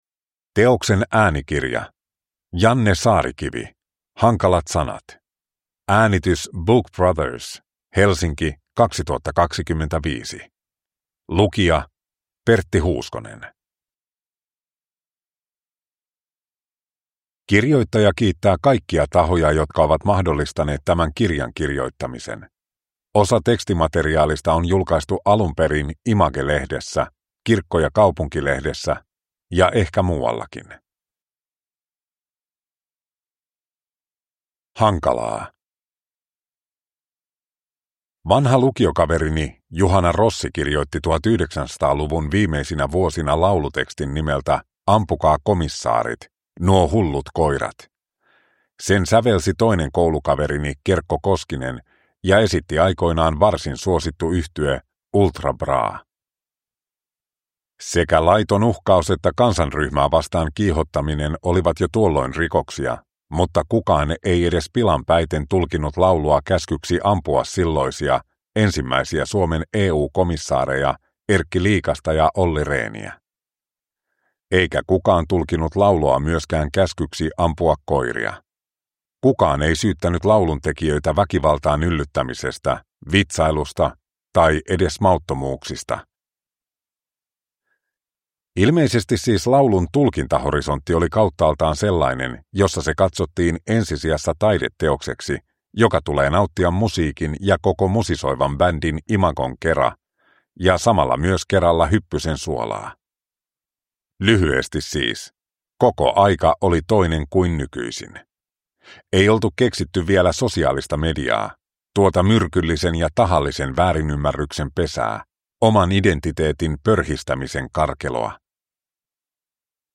Hankalat sanat – Ljudbok